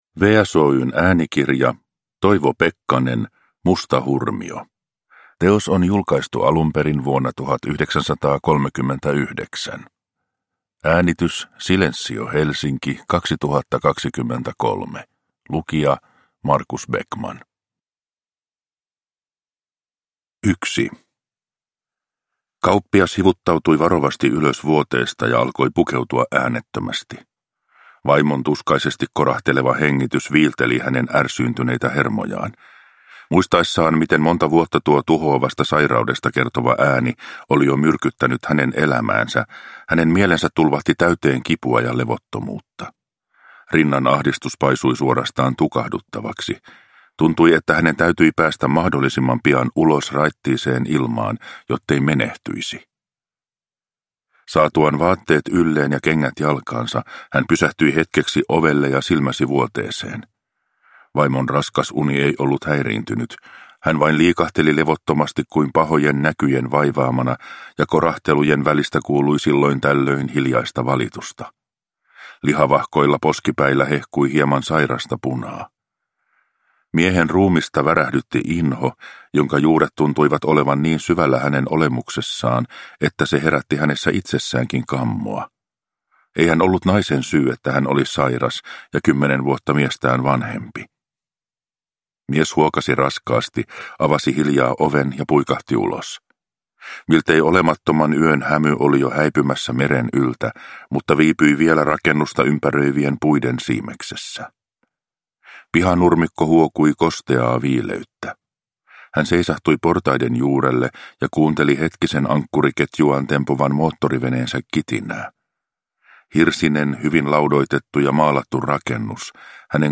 Musta hurmio – Ljudbok – Laddas ner